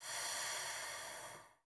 T BREATH 1.wav